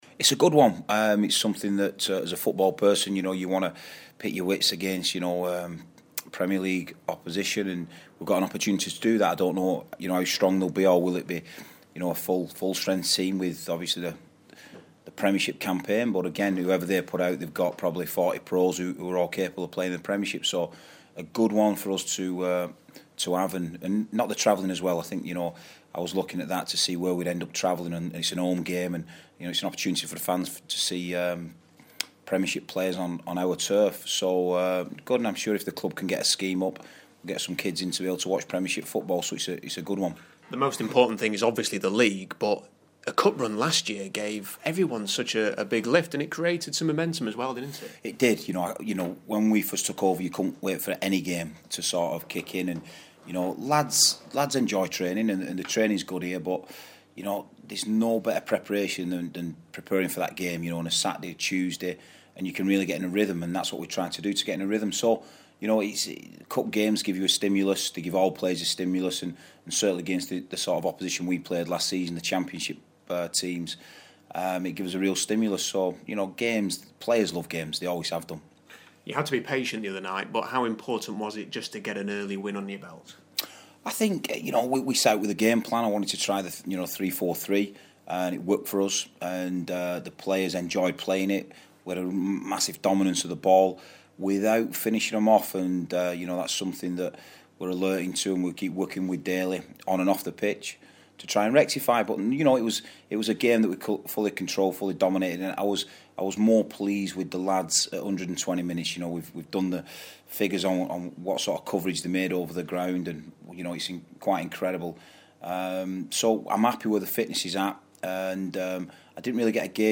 interview pre-Blackpool